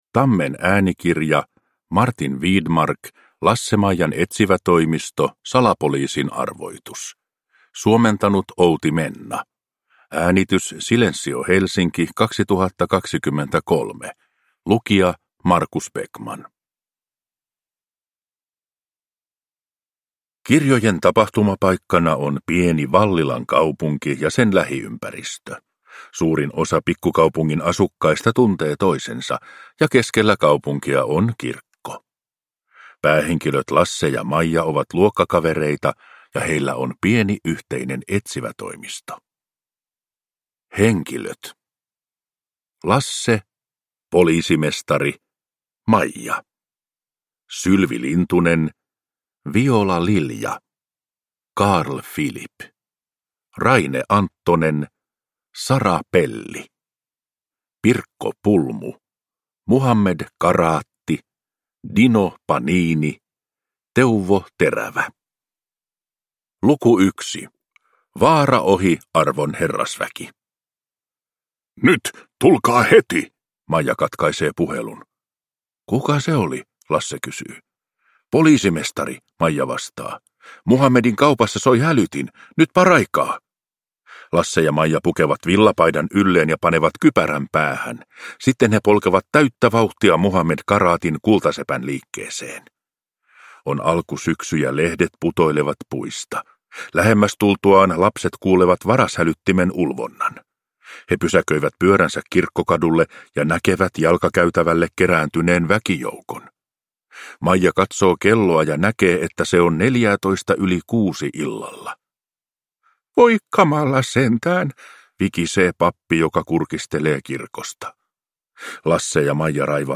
Salapoliisin arvoitus. Lasse-Maijan etsivätoimisto – Ljudbok – Laddas ner